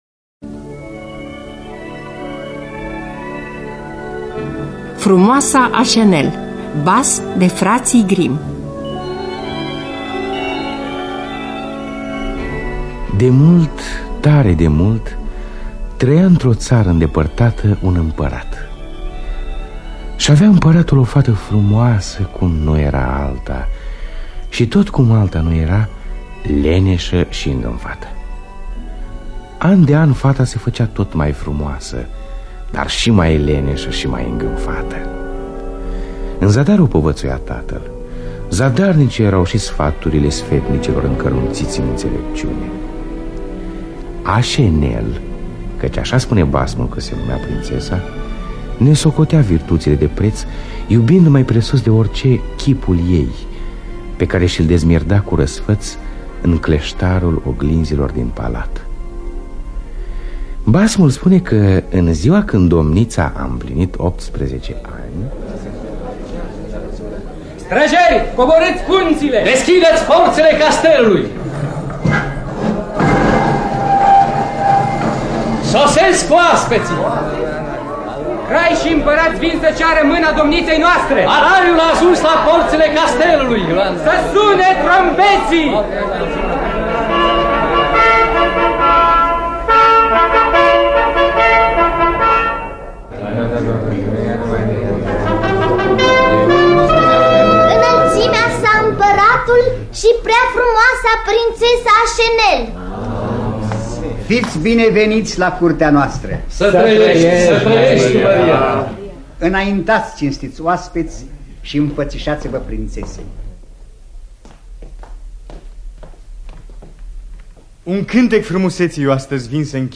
Dramatizarea radiofonică de Vasile Mănuceanu.